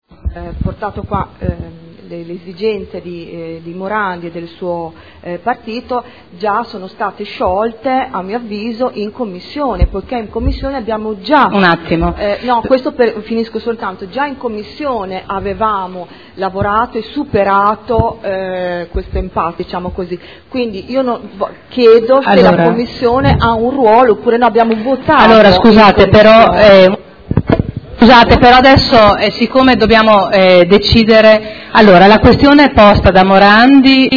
Ingrid Caporioni — Sito Audio Consiglio Comunale
Dibattito su pregiudiziale alla proposta di deliberazione. Nuovo Mercato Ortofrutticolo all’Ingrosso